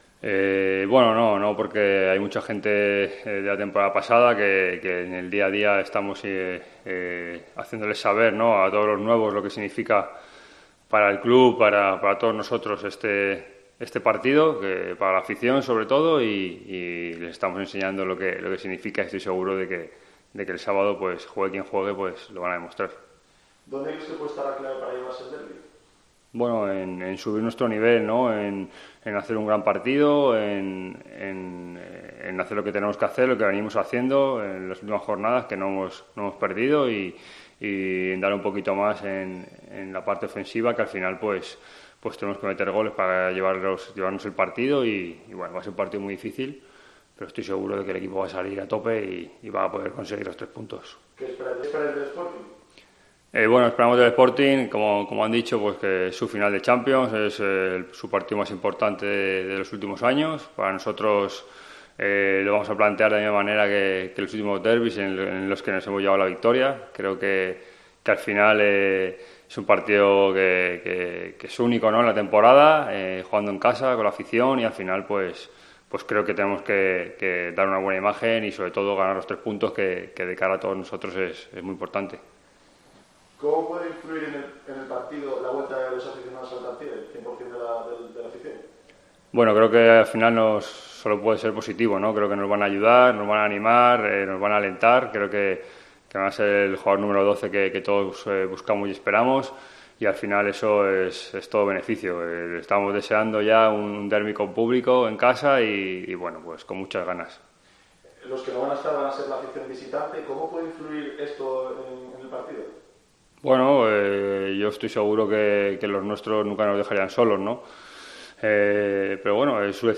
Rueda de prensa Alejandro Arribas (previa derbi)